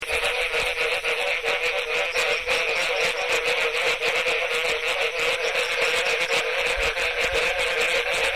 Breeding extends most of the way through the rainy season, and one can almost always hear their distinctive wonk-wonk-wonk calls from shrubs, small trees and the edges of temporary bodies of water.
S_baudinii.mp3